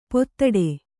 ♪ pottaḍe